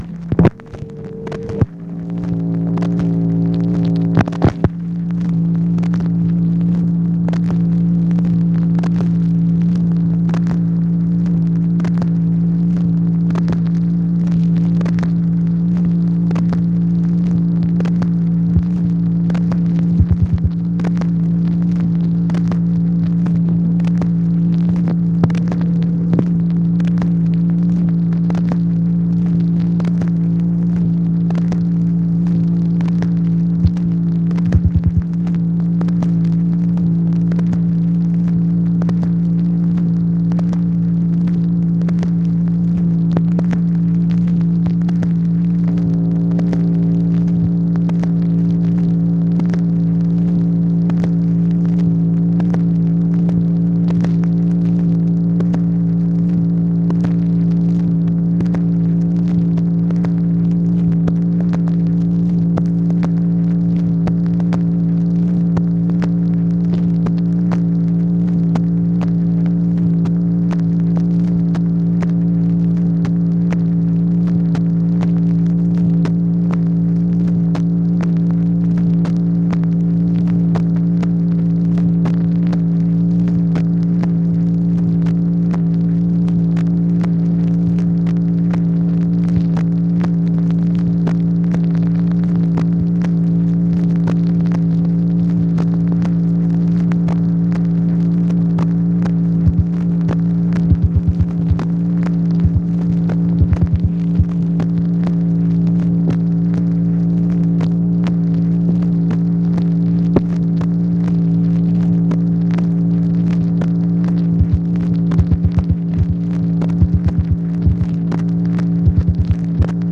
MACHINE NOISE, July 2, 1964
Secret White House Tapes | Lyndon B. Johnson Presidency